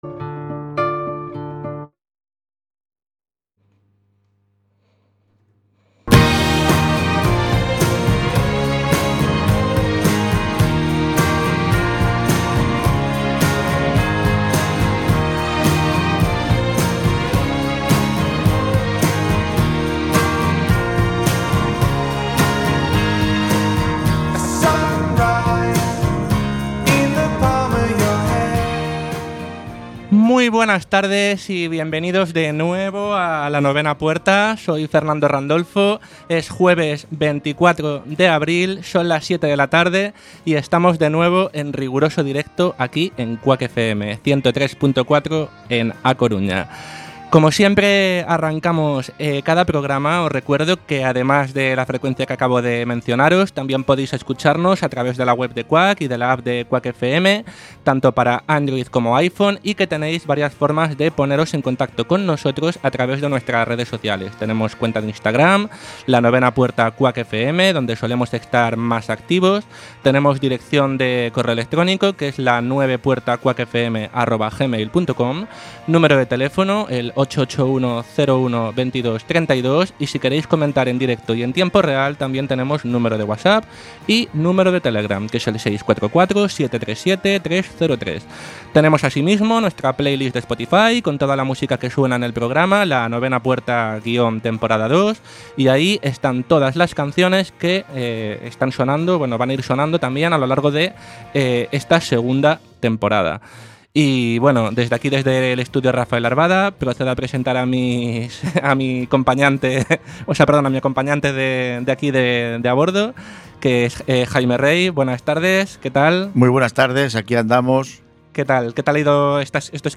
Programa de opinión y actualidad en el que se tratan temas diversos para debatir entre los colaboradores, con algún invitado relacionado con alguno de los temas a tratar en el programa y que además cuenta con una agenda de planes de ocio en la ciudad y alguna recomendación musical y de cine/series/libros.